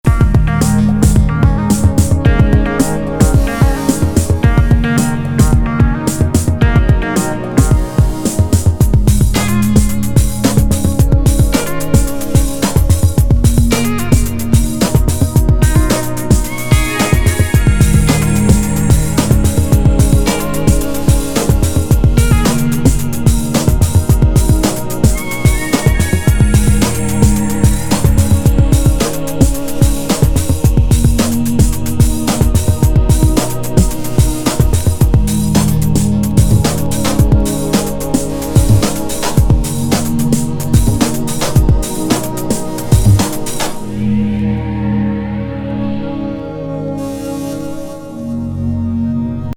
言わずと知れたデトロイト・テクノ金字塔的コンピ!インナー・フライト的ロボティック